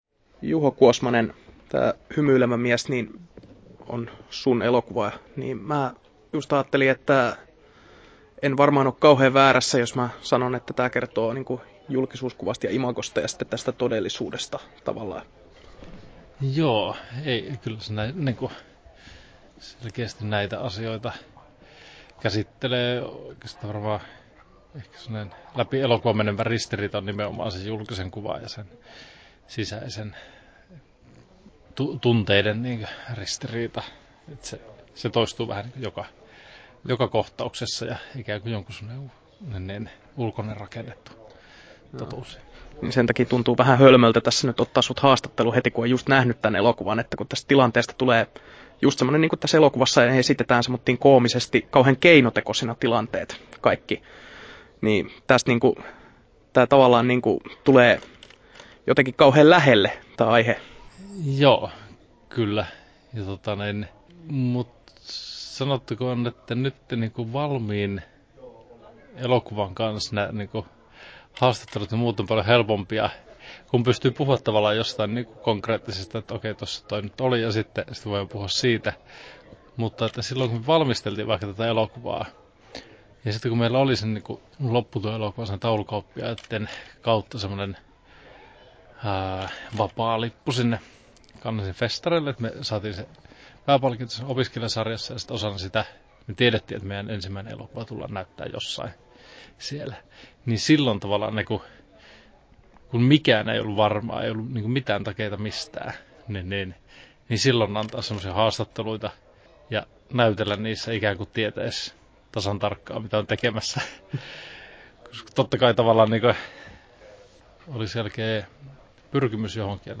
Haastattelussa Juho Kuosmanen Kesto: 16'48" Tallennettu: 16.8.2016, Turku Toimittaja